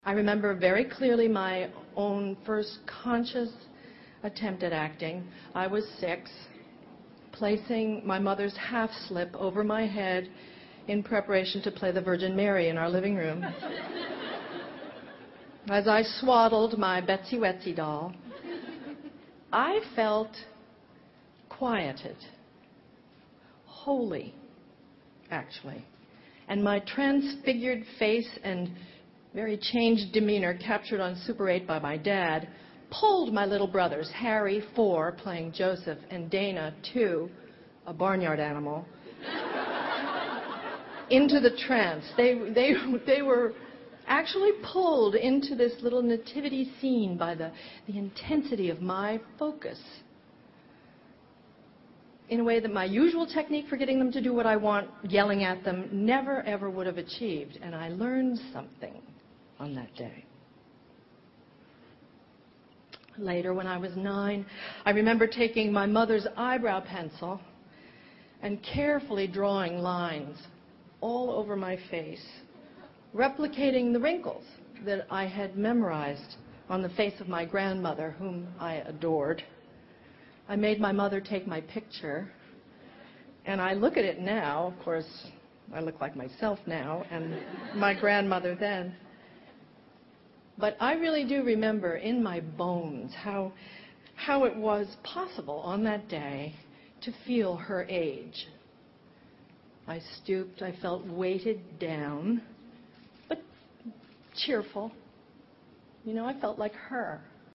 偶像励志英语演讲 46:让父母为你骄傲(6) 听力文件下载—在线英语听力室
在线英语听力室偶像励志英语演讲 46:让父母为你骄傲(6)的听力文件下载,《偶像励志演讲》收录了娱乐圈明星们的励志演讲。